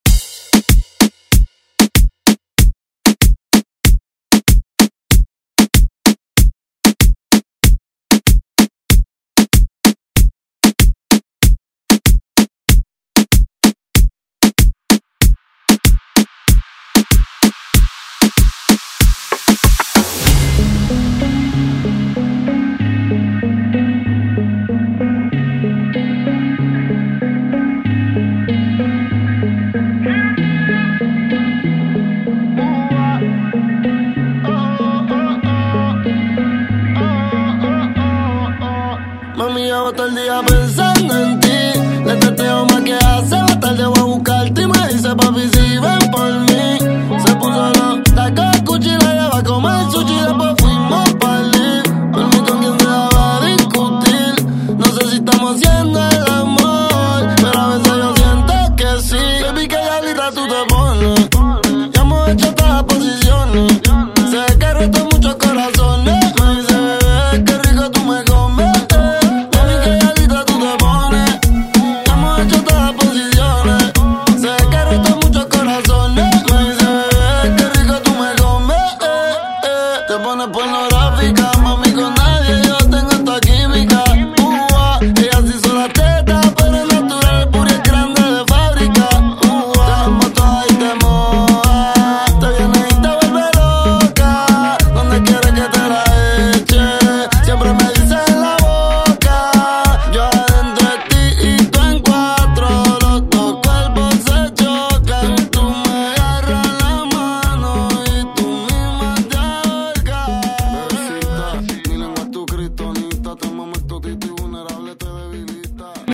Genres: 70's , RE-DRUM